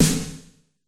Snare - Roland TR 44